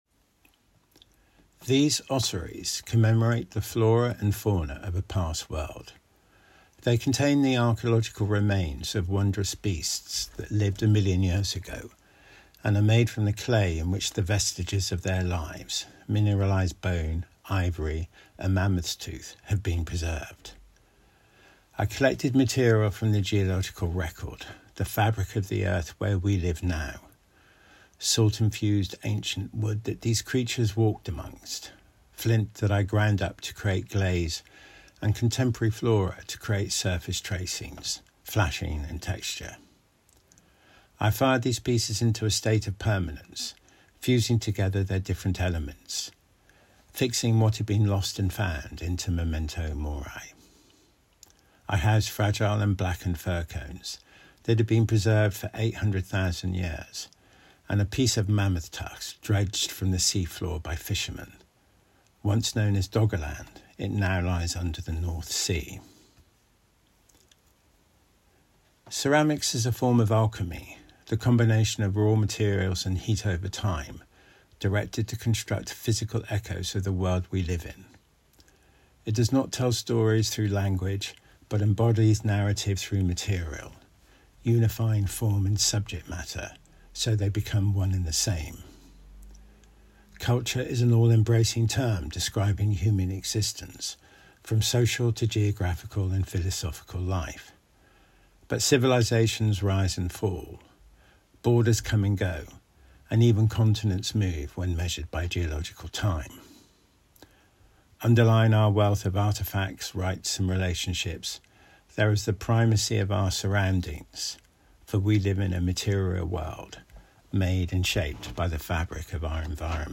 Listen to Julian Stair explaining his Ossuary series: